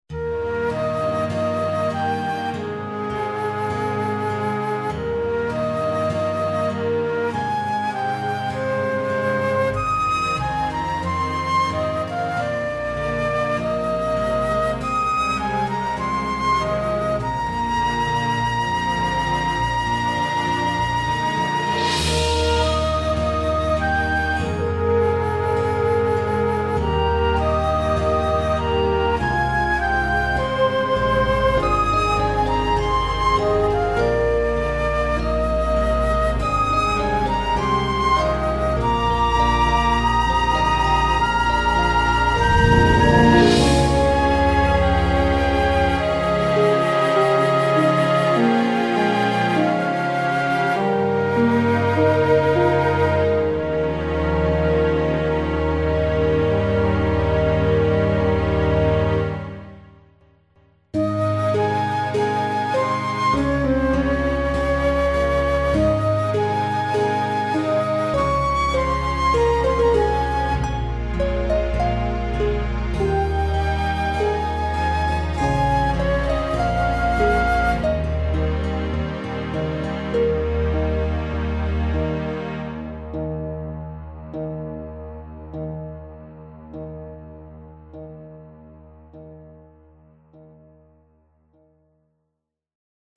Sintonía para el documental